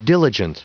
Prononciation du mot diligent en anglais (fichier audio)
Prononciation du mot : diligent